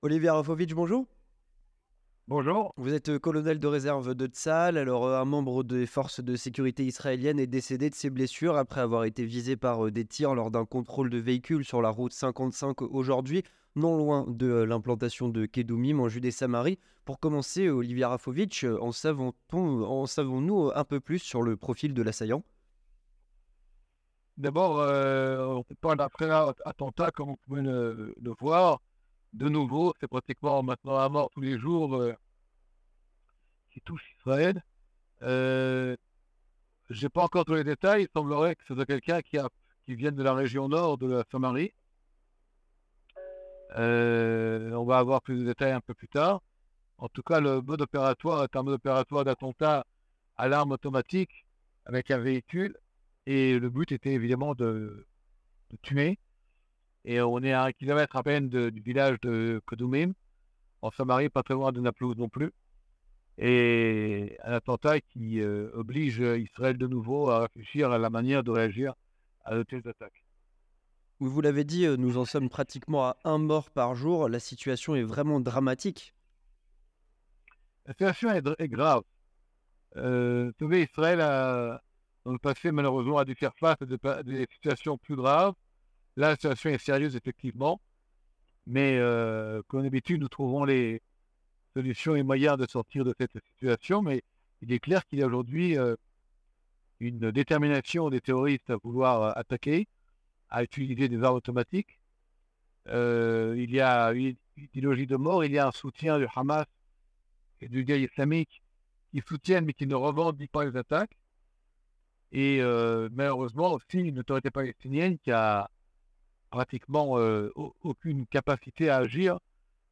Entretien du 18h - Attentat proche de l'implantation de Kedumim